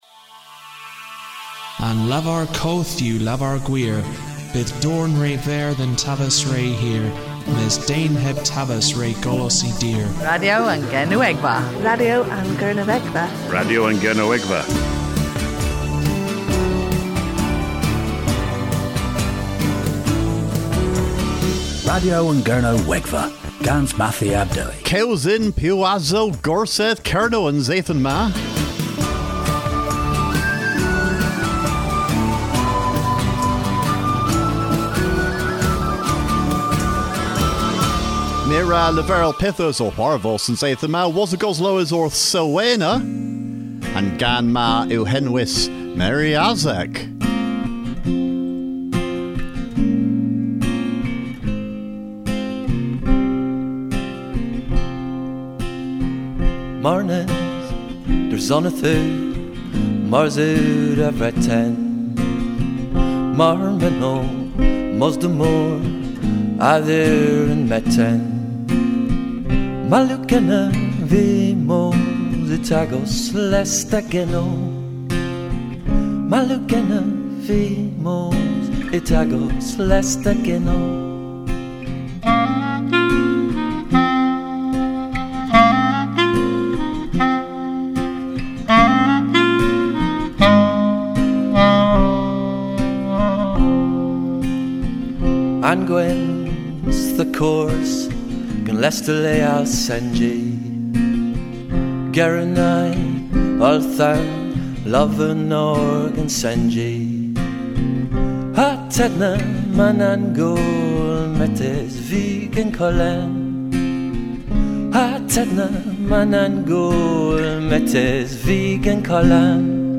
AGAN TOWLENNOW / OUR PROGRAMMES An Radyo is a weekly one hour radio show playing Cornish and Celtic music, presented in Cornish. We also have news in Cornish and sometimes an interview.